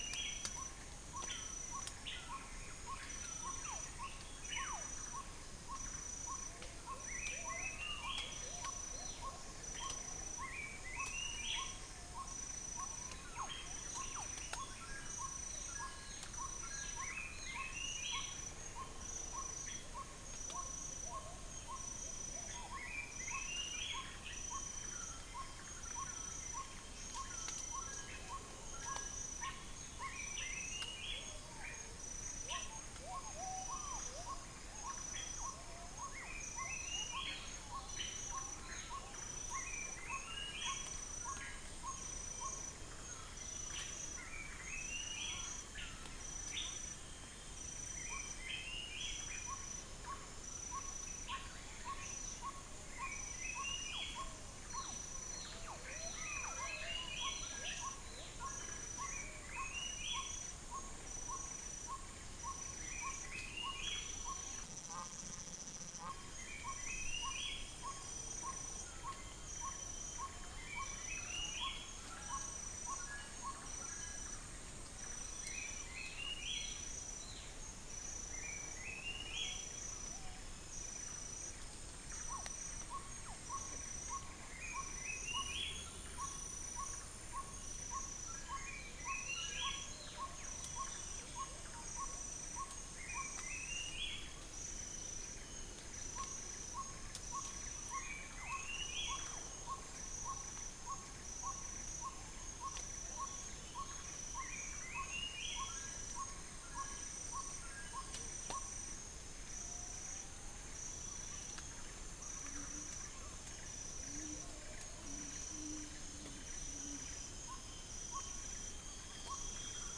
Stachyris nigricollis
Buceros rhinoceros
Gracula religiosa
Dicrurus paradiseus